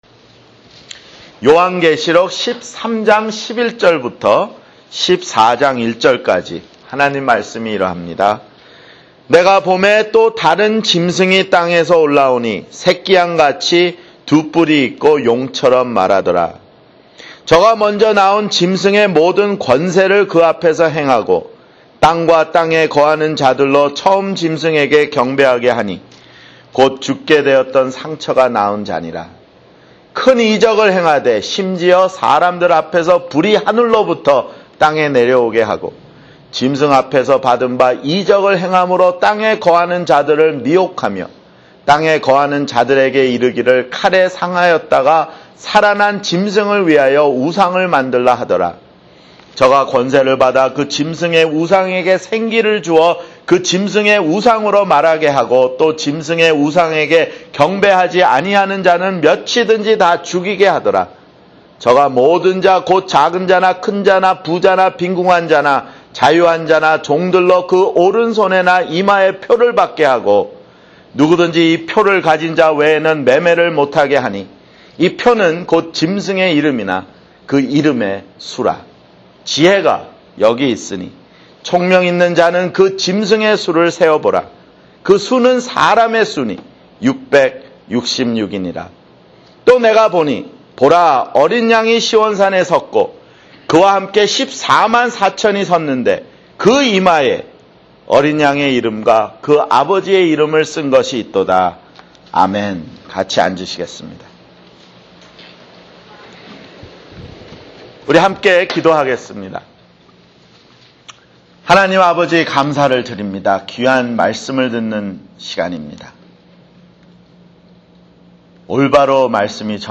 [주일설교] 요한계시록 (47)